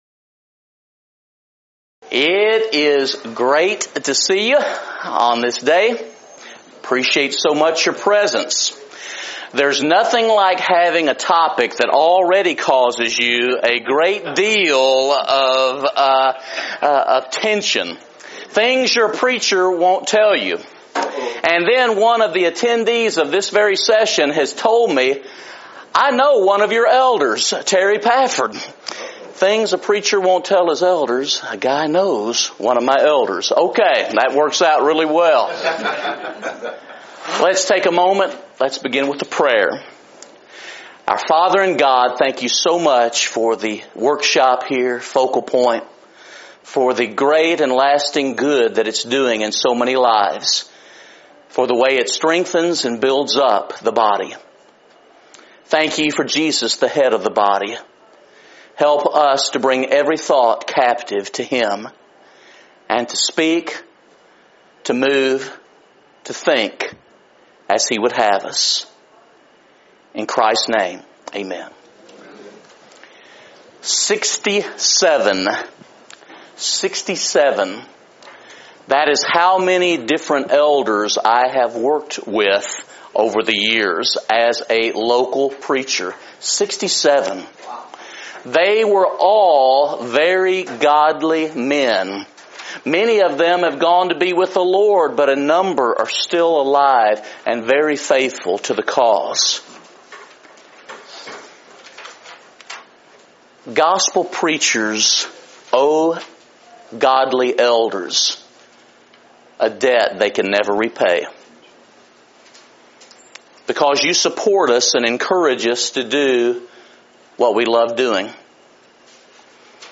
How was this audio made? Event: 2018 Focal Point